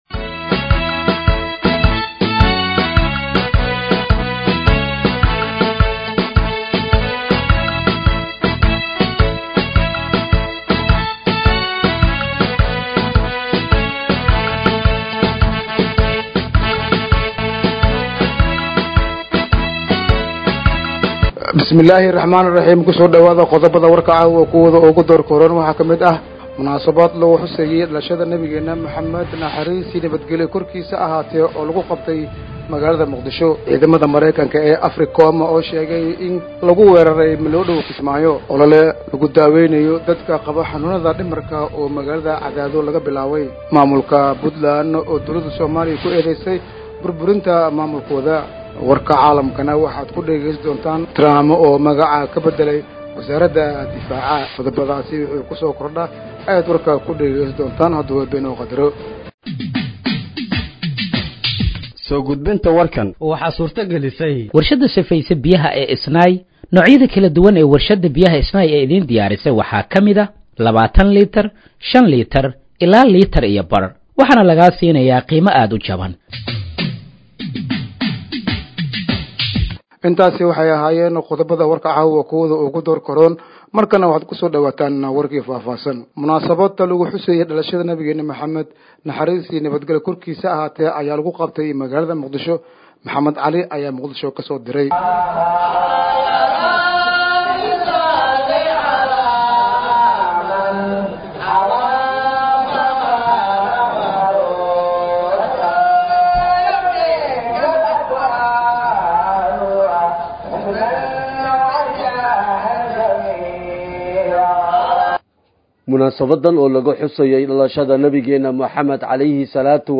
Dhageeyso Warka Habeenimo ee Radiojowhar 05/09/2025